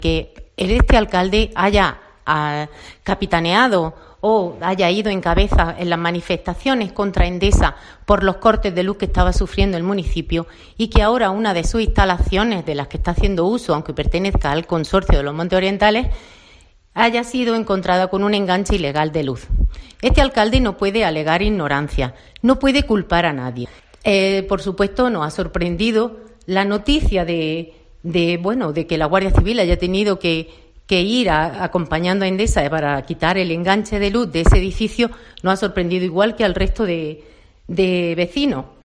Ana Belén Garrido, portavoz del PP en Iznalloz